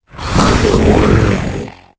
f6f85fab1b4fc8029ebed0be62d5c3ffa9bffb4d infinitefusion-e18 / Audio / SE / Cries / DREDNAW.ogg infinitefusion d3662c3f10 update to latest 6.0 release 2023-11-12 21:45:07 -05:00 11 KiB Raw History